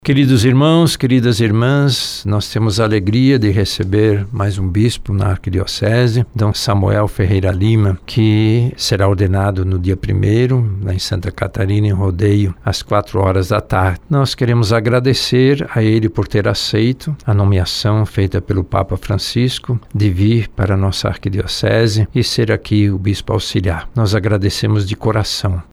Frei Samuel, em breve assumirá a missão de bispo auxiliar da Arquidiocese de Manaus, como explica o Cardeal Leonardo Steiner.